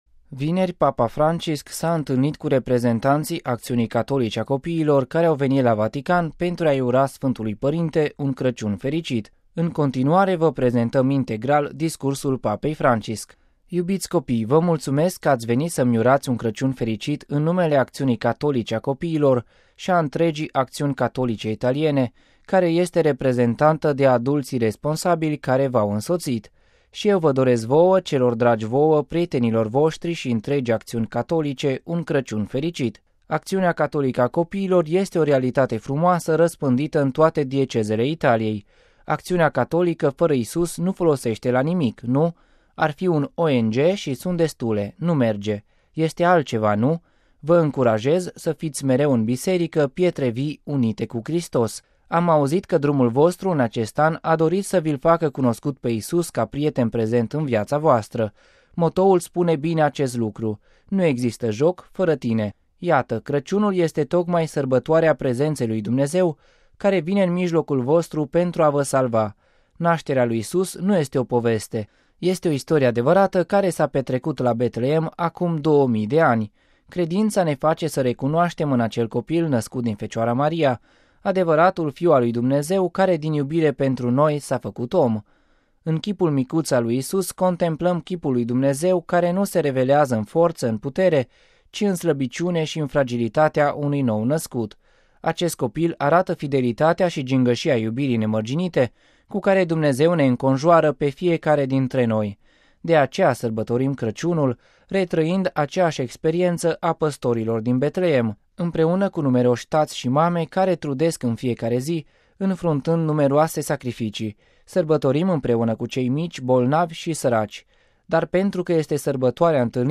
(RV 20 dec 2013) Vineri, Papa Francisc s-a întâlnit cu reprezentanţii Acţiunii Catolice a Copiilor care au venit la Vatican pentru a-i ura Sfântului Părinte un Crăciun Fericit. În continuare vă prezentăm integral discursul Papei Francisc: RealAudio